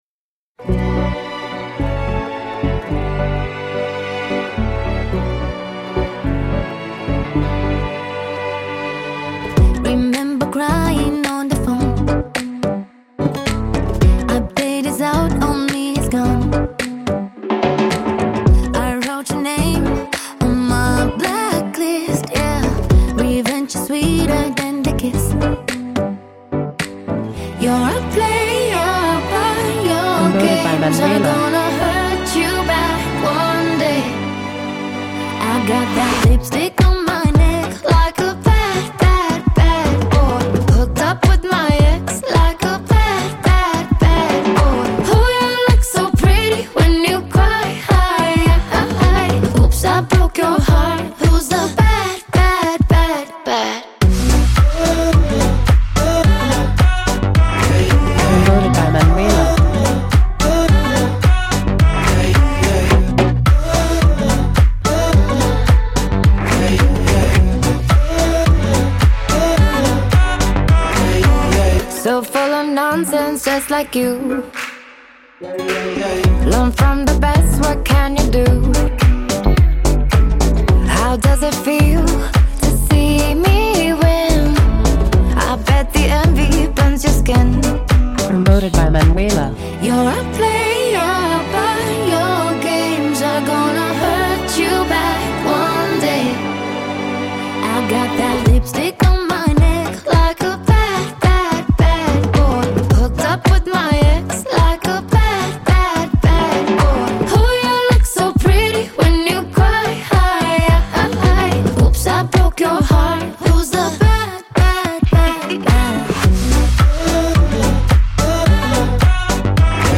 dance-pop
saxophone
Radio Edit